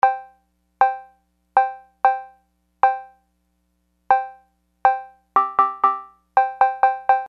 TR-808_Cowbell Old style analog beatbox (TR-808) cowbell.
TR-808_Cowbell.mp3